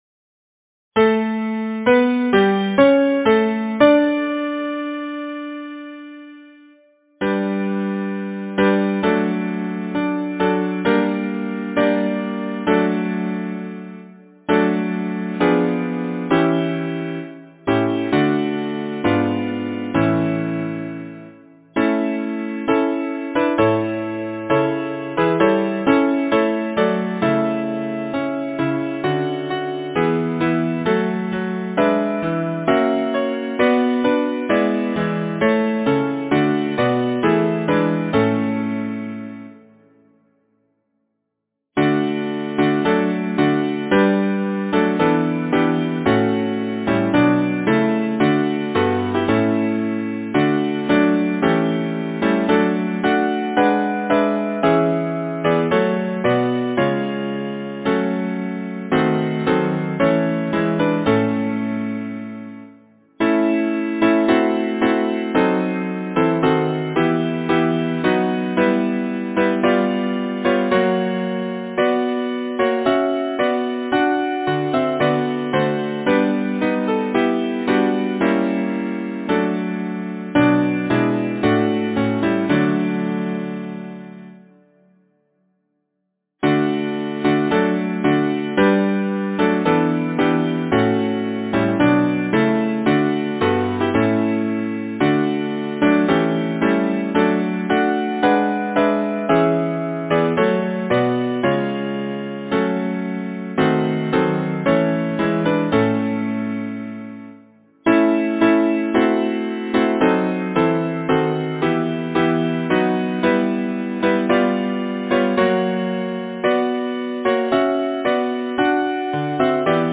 Title: Pipe! Red Lip’d Autumn Pipe Composer: Henry Thomas Smart Lyricist: Frederick Enoch Number of voices: 4vv Voicing: SATB Genre: Secular, Partsong
Language: English Instruments: A cappella